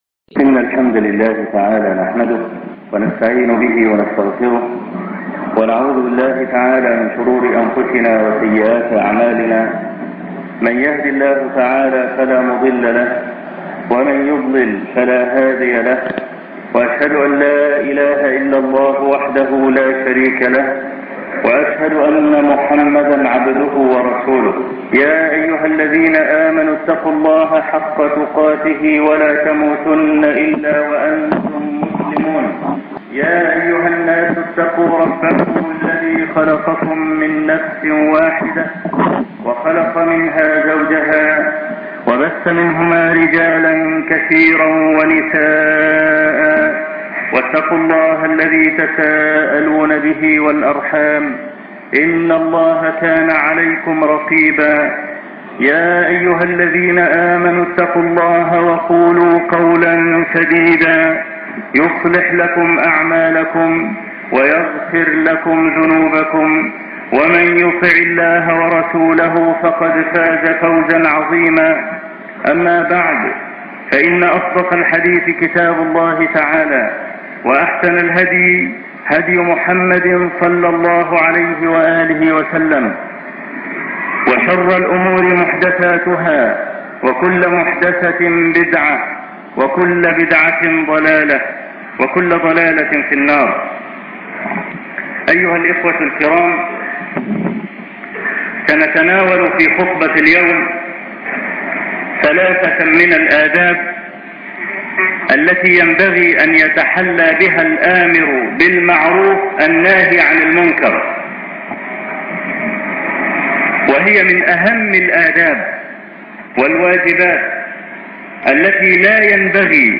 الأمر بالمعروف والنهي عن المنكر- خطبة الجمعة - الشيخ أبو إسحاق الحويني